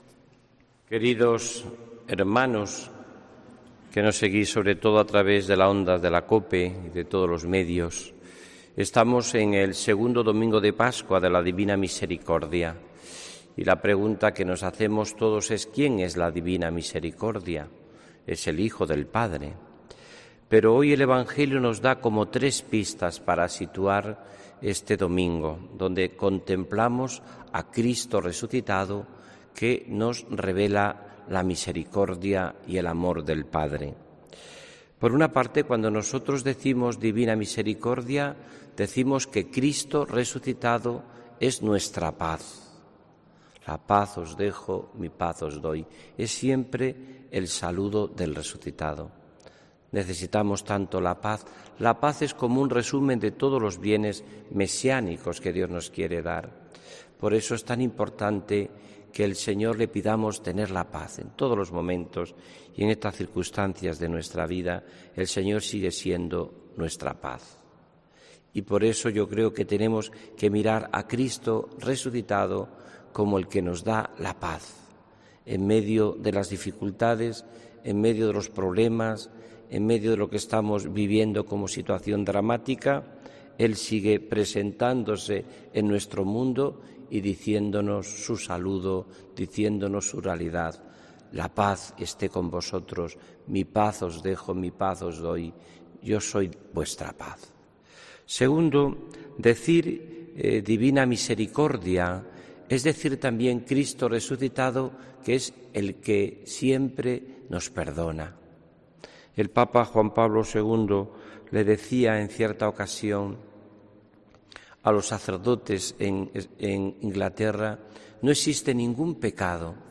HOMILÍA 19 ABRIL 2020